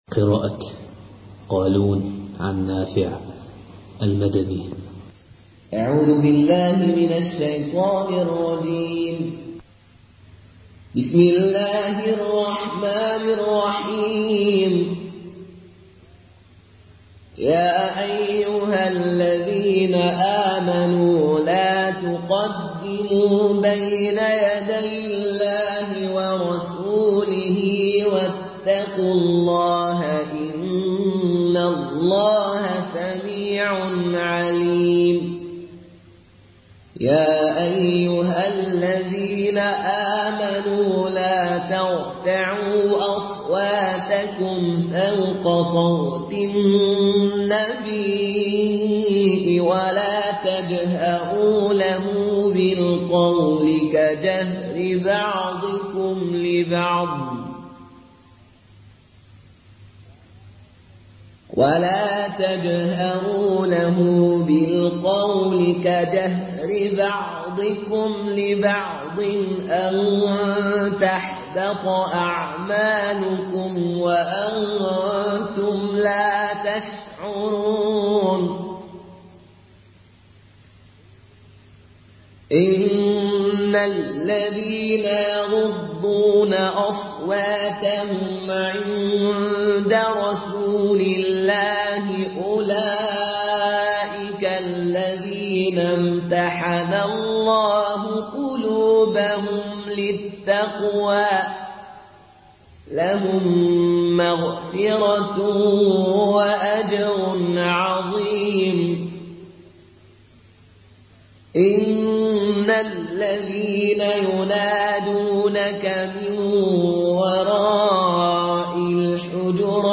Qaloon Narration
Murattal